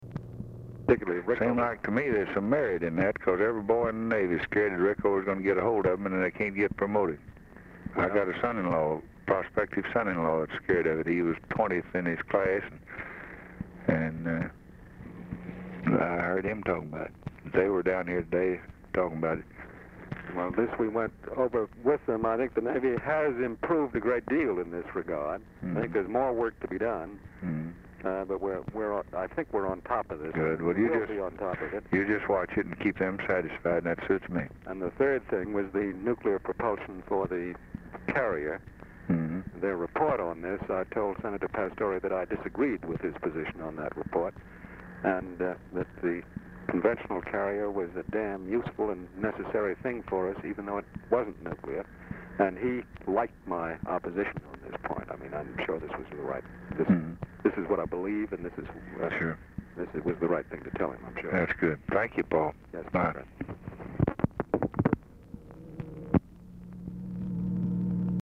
Telephone conversation # 481, sound recording, LBJ and PAUL NITZE, 12/13/1963, 6:15PM | Discover LBJ
RECORDING STARTS AFTER CONVERSATION HAS BEGUN
Format Dictation belt
Location Of Speaker 1 Oval Office or unknown location